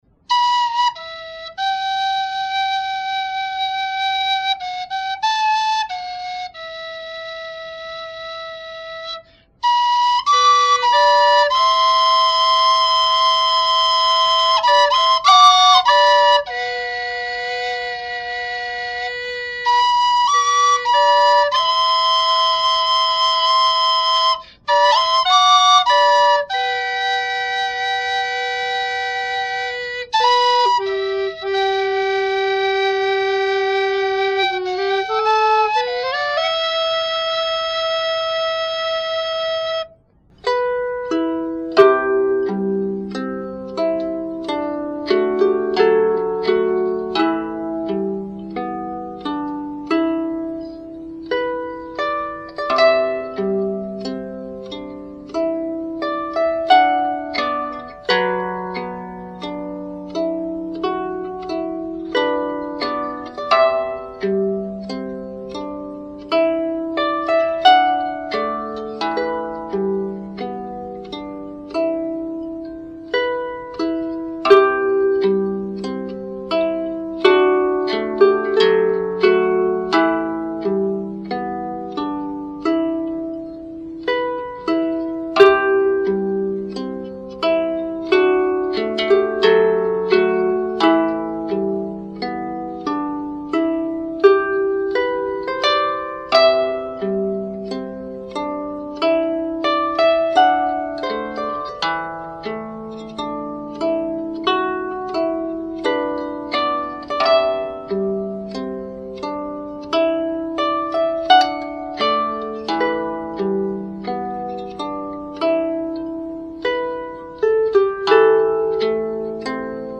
here is a traditional Irish song. Though I am not singing the lyrics today, I hope you enjoy the melody on Oriole recorder, alto recorder, and lyre!